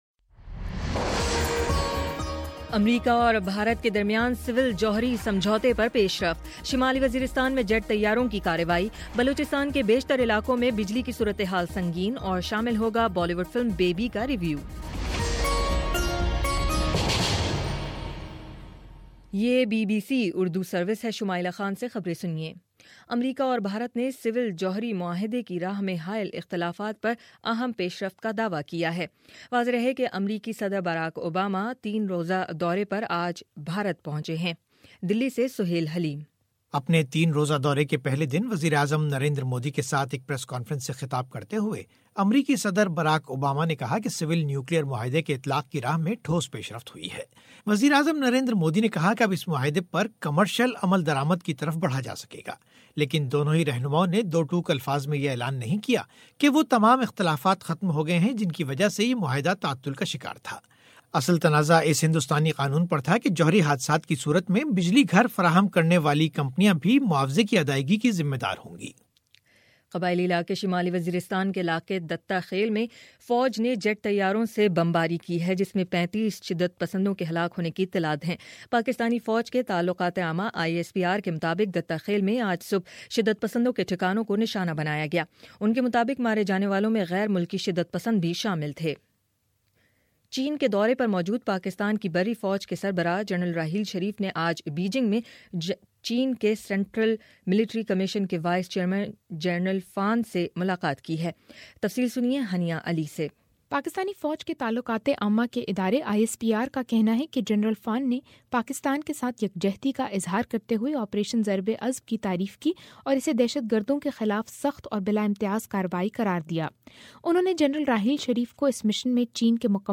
جنوری 25: شام چھ بجے کا نیوز بُلیٹن